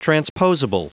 Prononciation du mot transposable en anglais (fichier audio)
Prononciation du mot : transposable